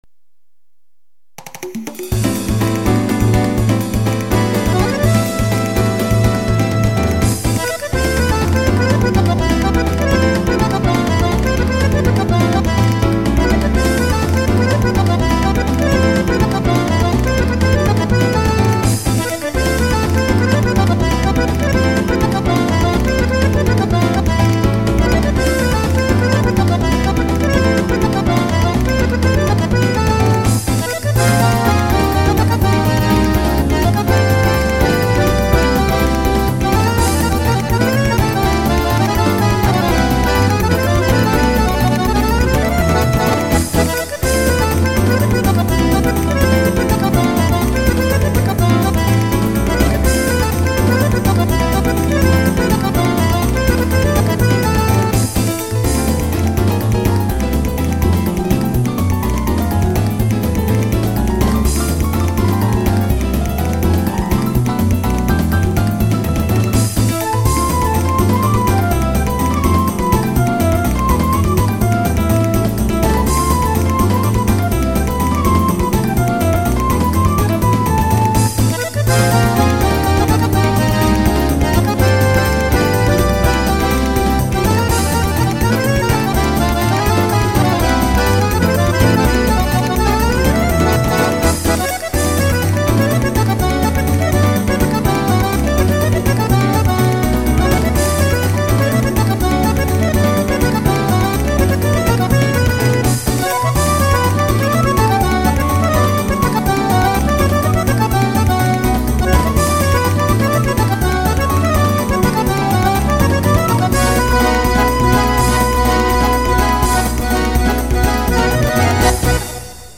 BPM124-124